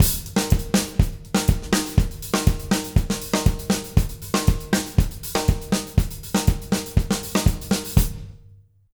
120ZOUK 04-L.wav